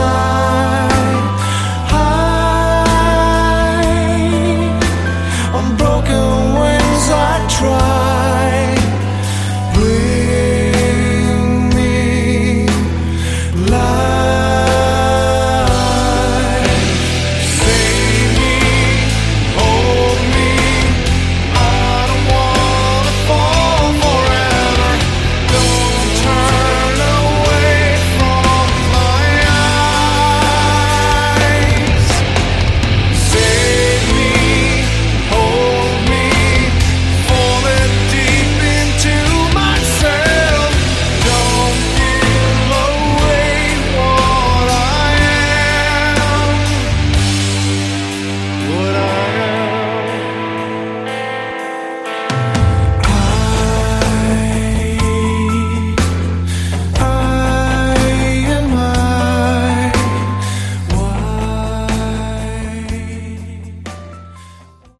Category: Melodic Hard Rock
Vocals
Guitars
Drums
Bass
Keyboards